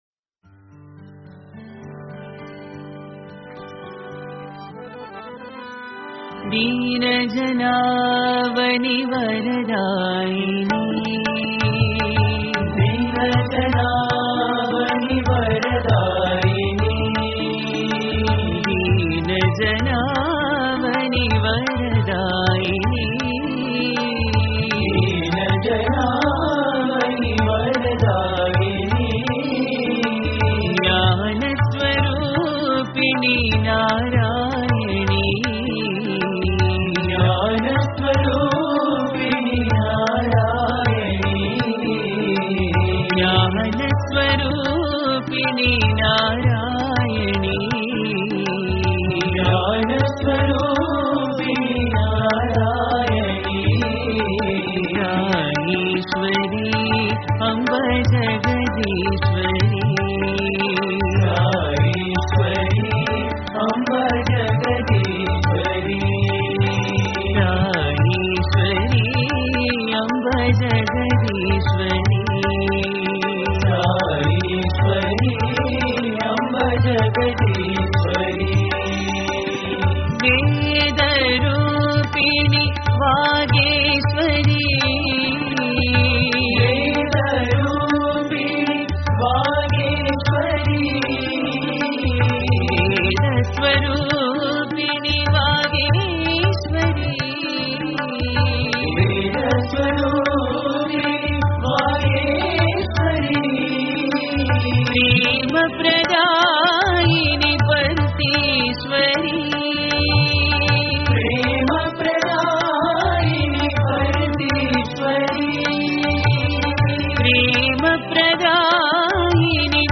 Related Bhajan